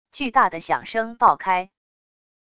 巨大的响声爆开wav音频